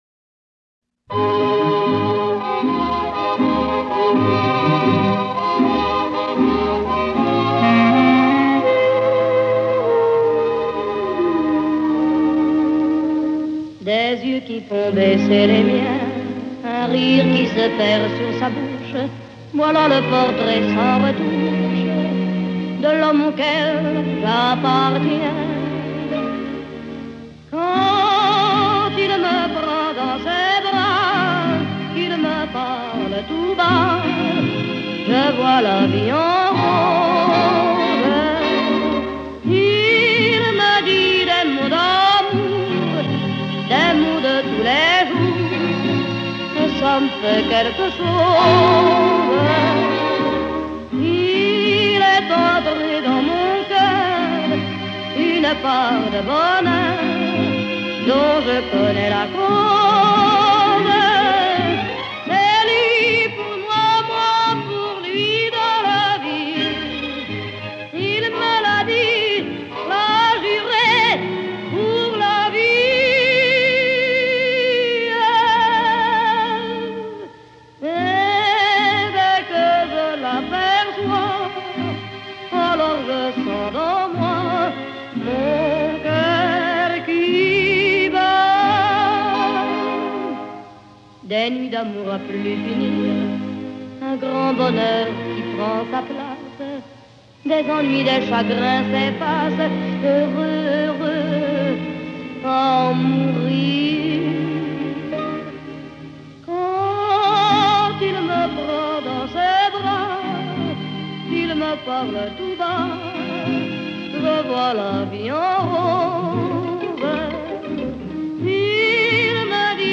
演唱版